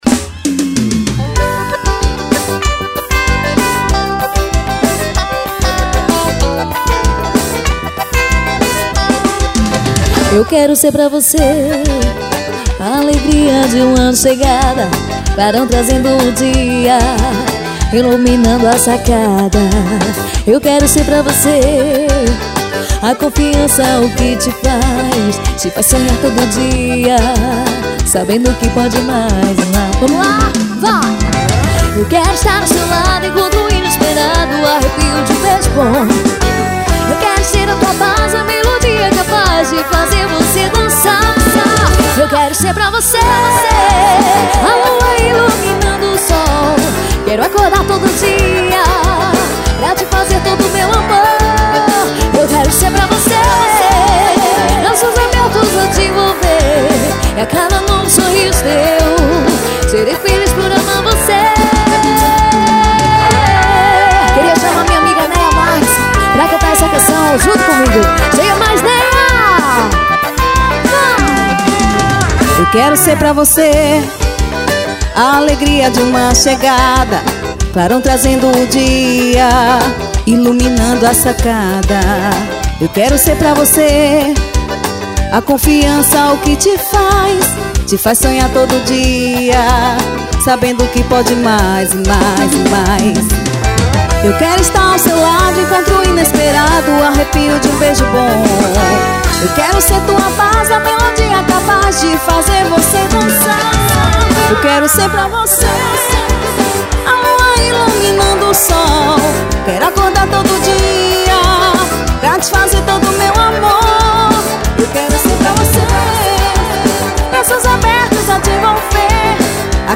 coração de menina ao vivo.